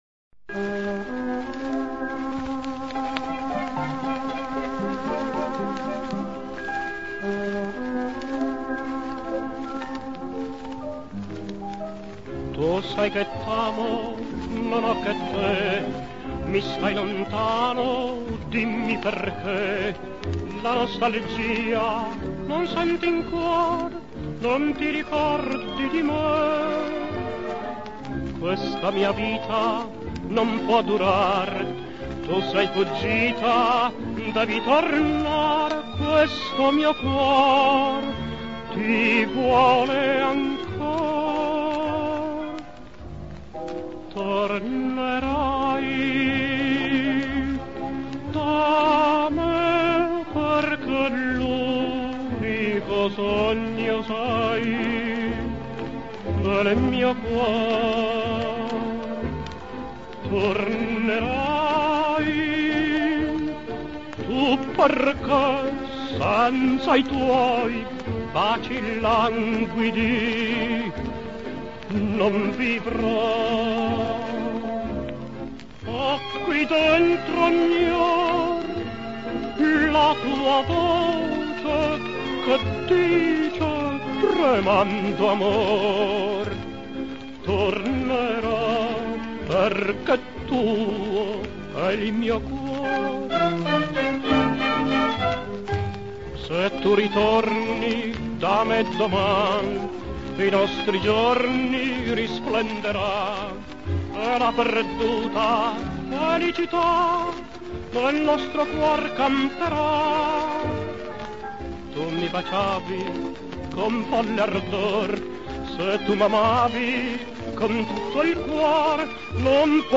Da supporto 78 giri